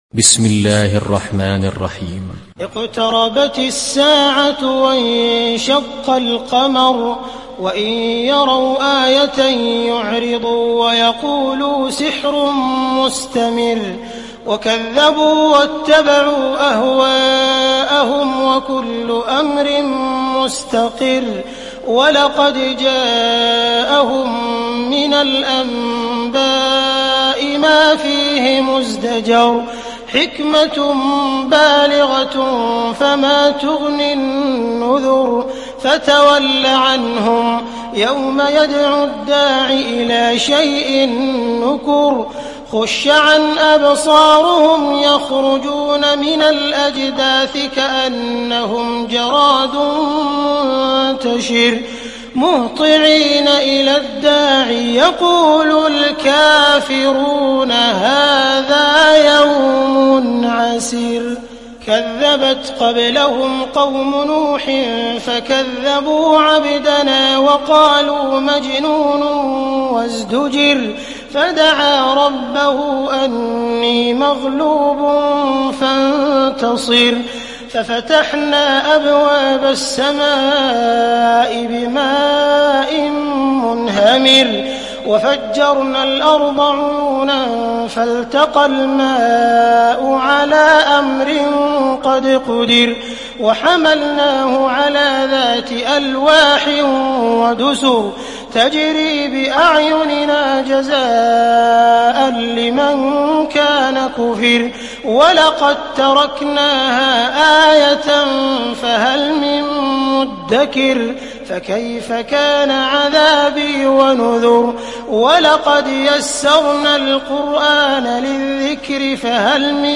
دانلود سوره القمر mp3 عبد الرحمن السديس (روایت حفص)